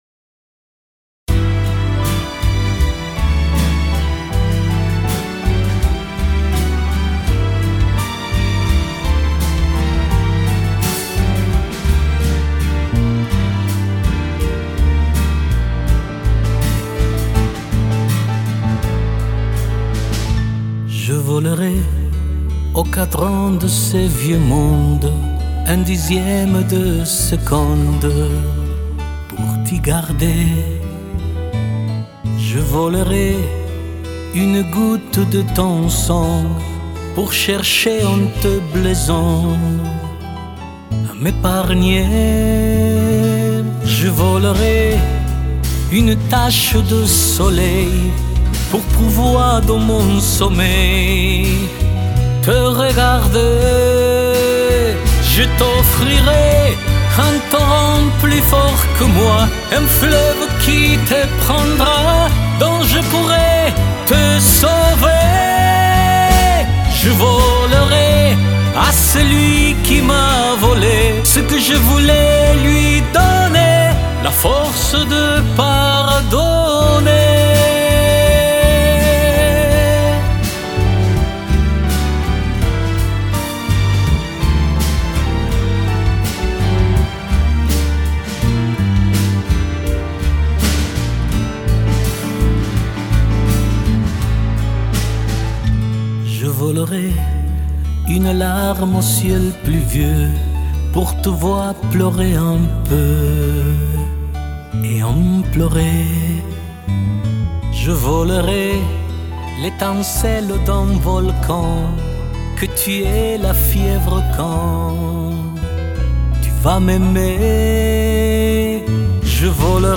Music Cover